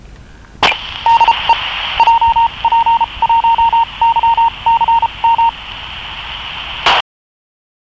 emoji439.98MHz JP1YCMのID（1990年?月?日、カセットテープに録音）